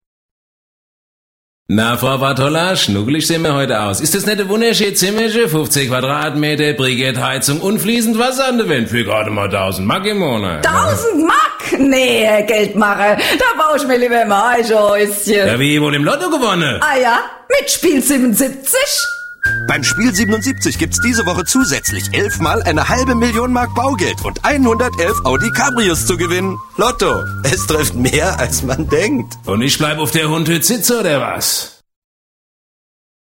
deutscher Profi-Sprecher mit einer großen Leidenschaft für Dokumentationen, Hörbücher und Dialekte
Sprechprobe: eLearning (Muttersprache):
german voice over artist